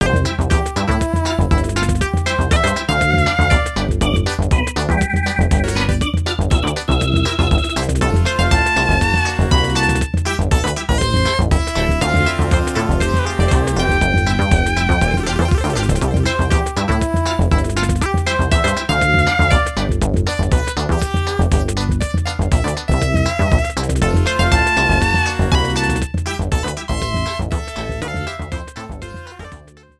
Cropped to 30 seconds, fade out added